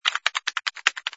sfx_keyboard_flurry02.wav